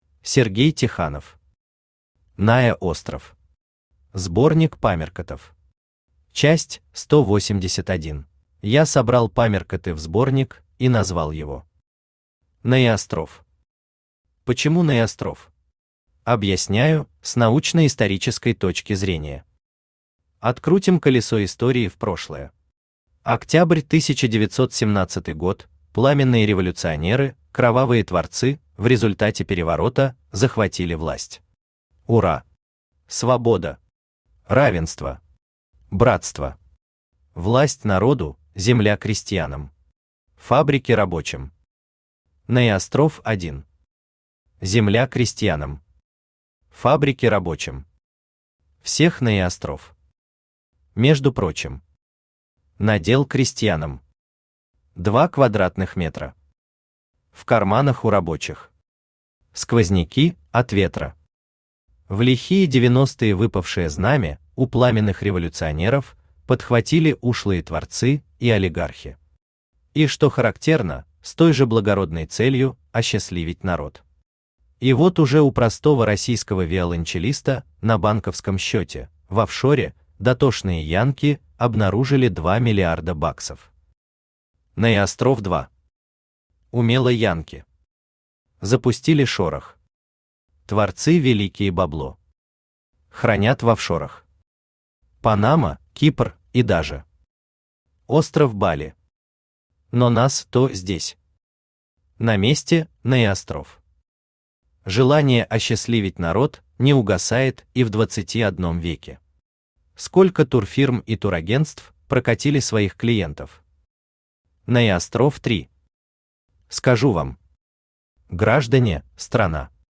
Аудиокнига НаеОстров. Сборник памяркотов. Часть 181 | Библиотека аудиокниг
Читает аудиокнигу Авточтец ЛитРес.